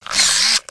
rifle_lower.wav